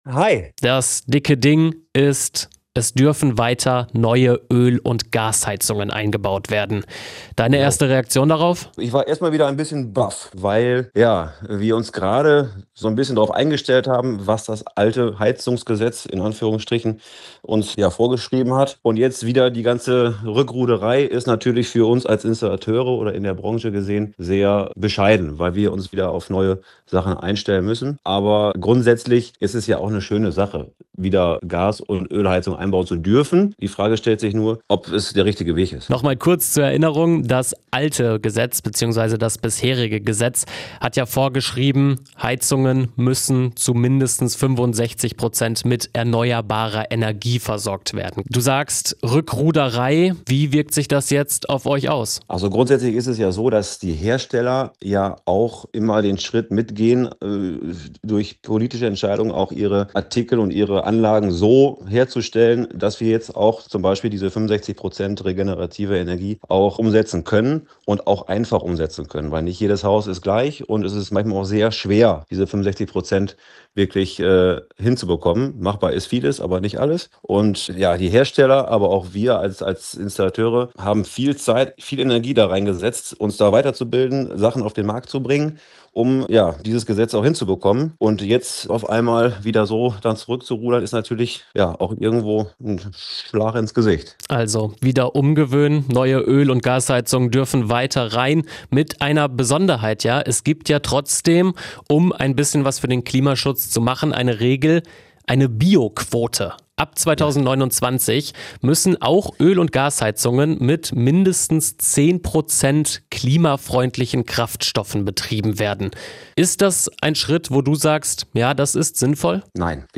Anzeige Exklusiv-Interview